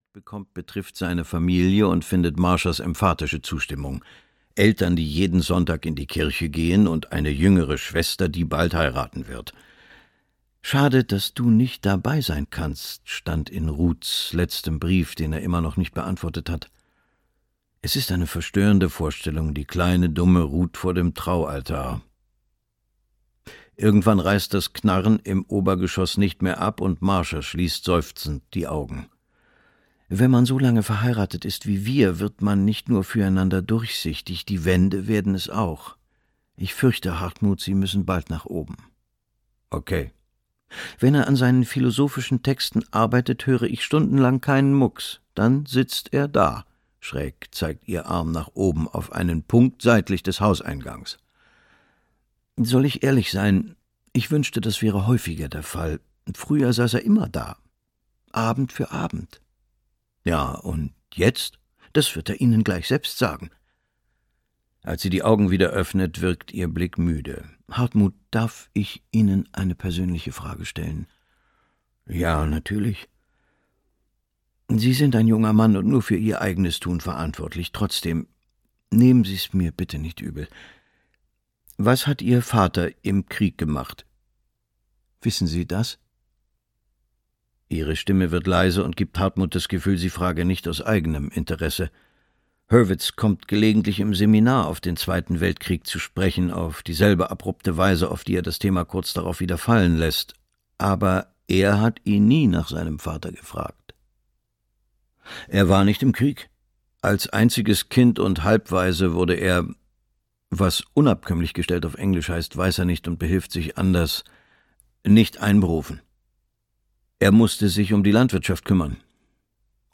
Fliehkräfte - Stephan Thome - Hörbuch